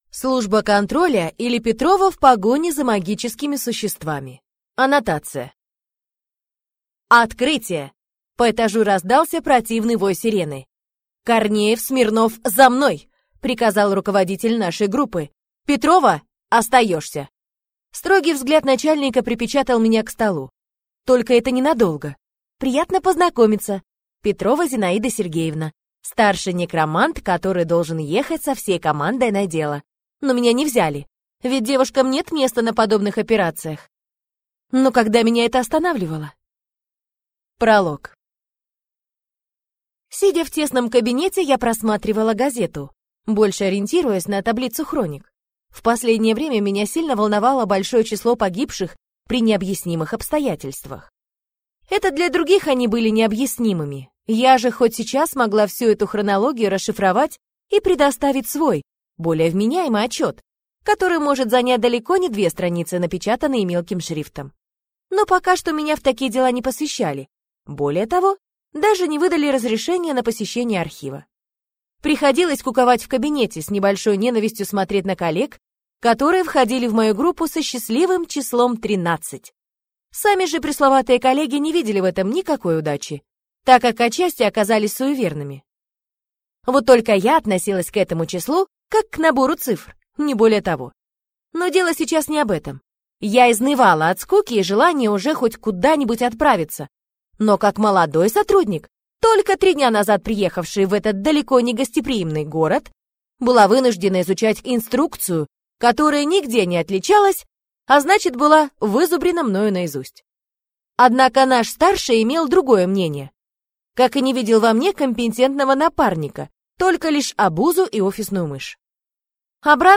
Аудиокнига Служба контроля, или Петрова в погоне за магическими существами | Библиотека аудиокниг